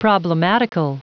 Prononciation du mot problematical en anglais (fichier audio)
Prononciation du mot : problematical